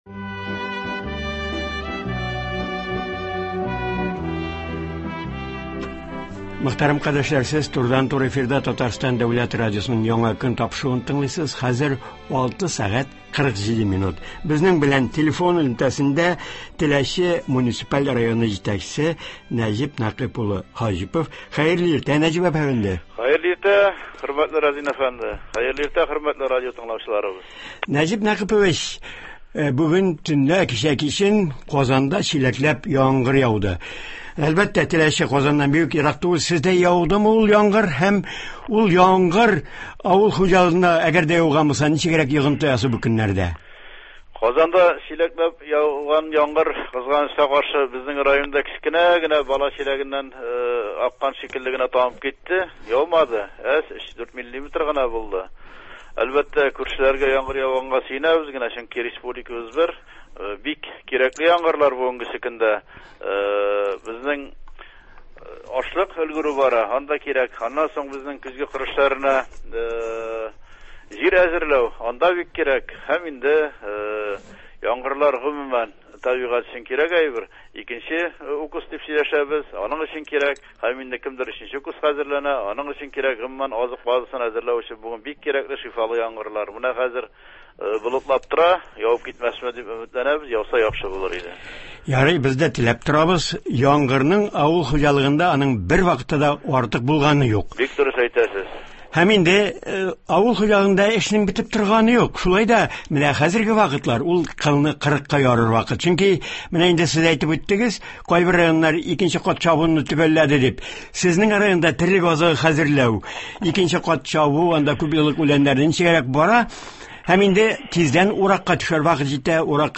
Теләче районында терлек азыгы хәзерләү оешкан төстә дәвам итә, терлекчелек продуктлары җитештерү арта. Болар хакында турыдан-туры эфирда телефон элемтәсе аша район хакимияте башлыгы Нәҗип Хаҗипов сөйләячәк.